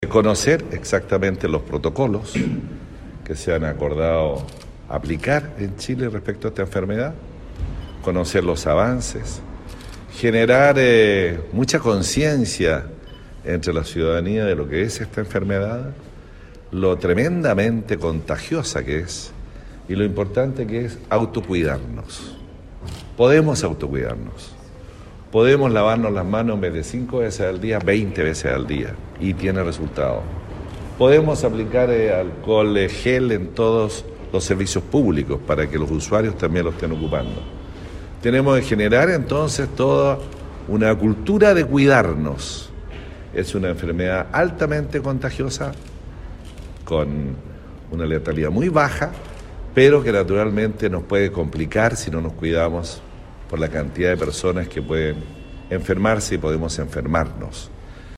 El intendente de Los Lagos Harry Jurgensen hizo un llamado a la comunidad a seguir las instrucciones del ministerio de Salud respecto del avance del coronavirus en el país.